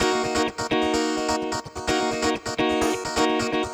VEH3 Electric Guitar Kit 1 128BPM
VEH3 Electric Guitar Kit 1 - 14 D# min.wav